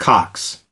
Transcription and pronunciation of the word "cox" in British and American variants.